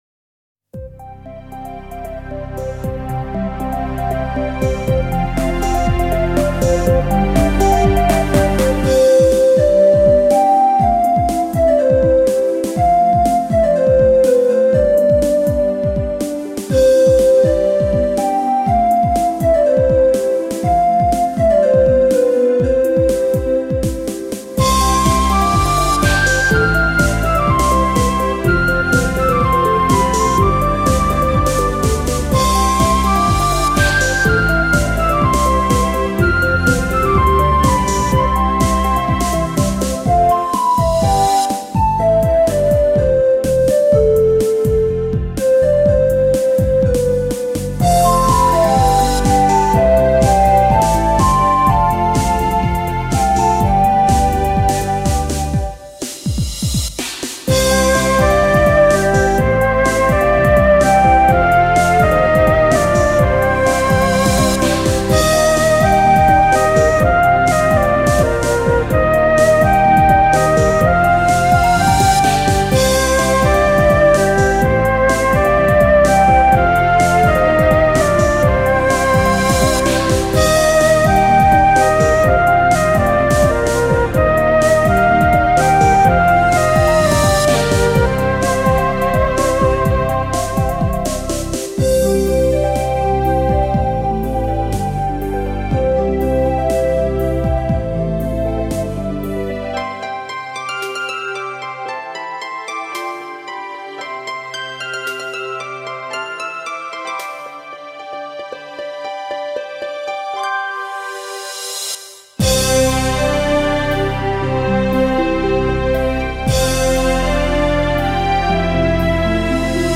星空のように青く深く、クリスタルのように透き通った、神秘と安らぎのサウンドが登場。